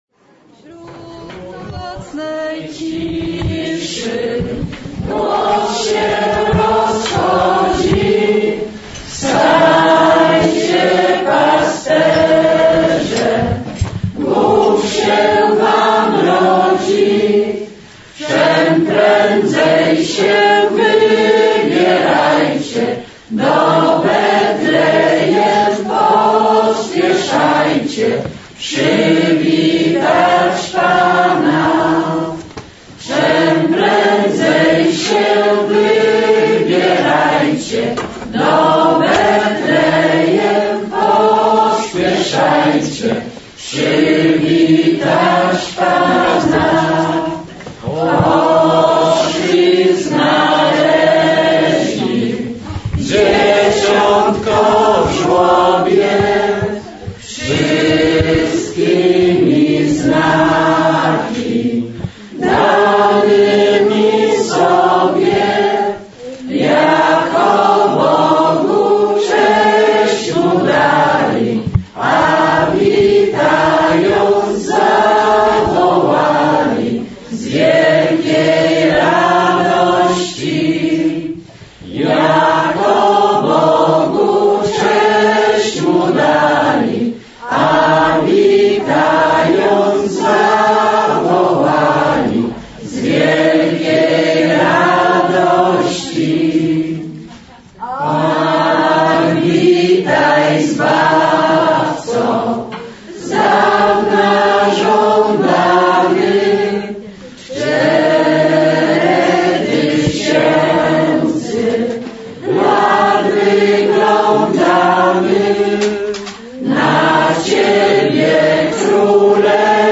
Spotkanie opłatkowe w diecezji kieleckiej